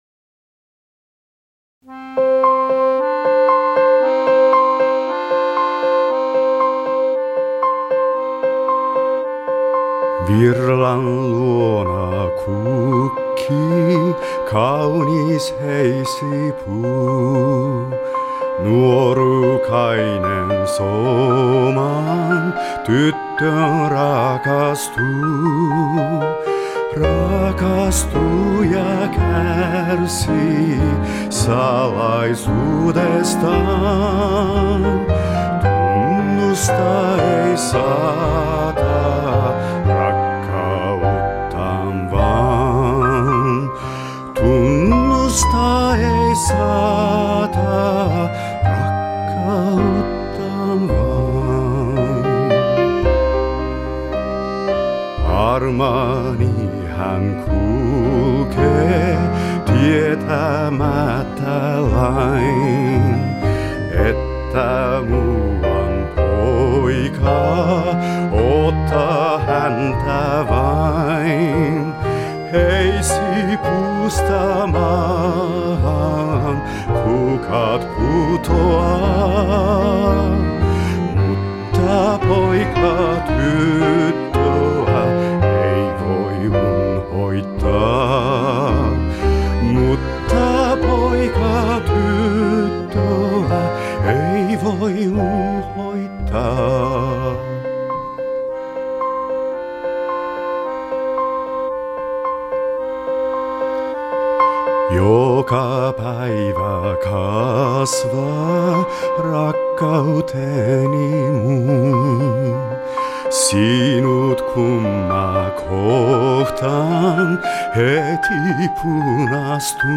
Советская песня на финском с японским акцентом